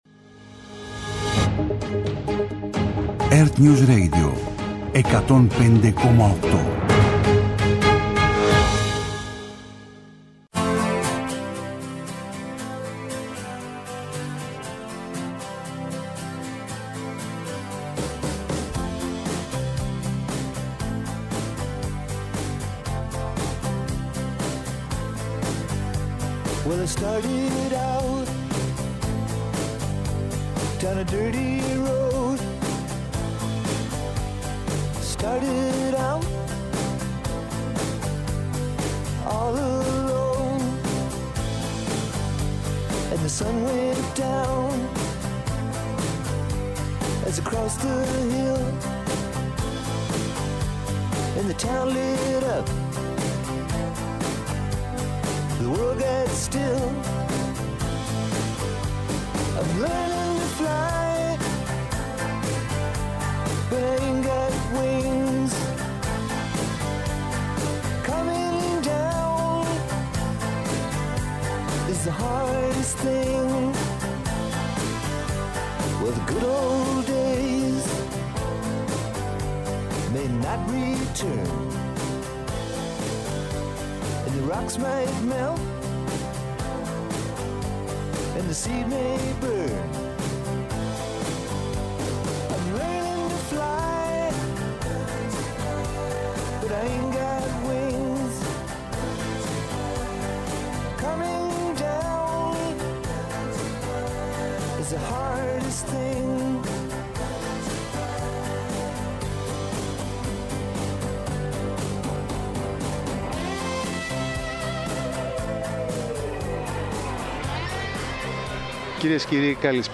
Απόψε, από το 11ο Οικονομικό Φόρουμ των Δελφών